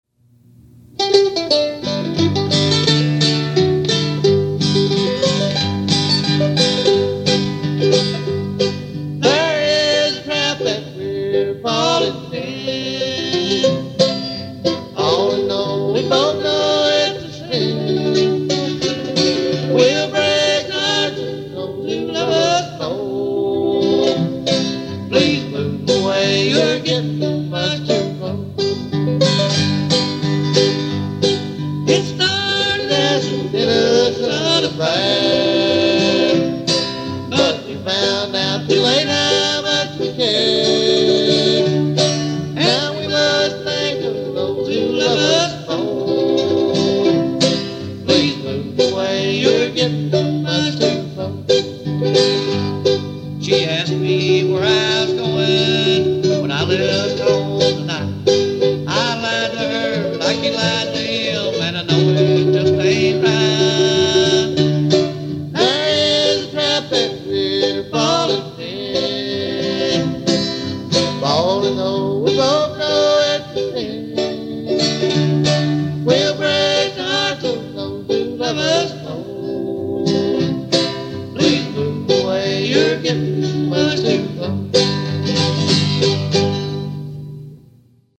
bluegrass music
recorded in the early 60's